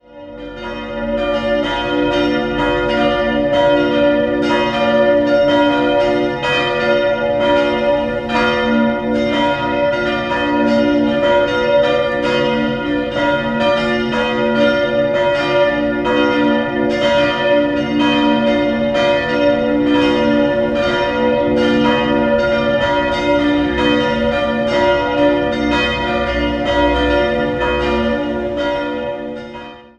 3-stimmiges H-Dur-Geläut: h'-dis''-fis'' Die mittlere Glocke stammt aus dem Jahr 1398, die beiden anderen wurden 1494 gegossen.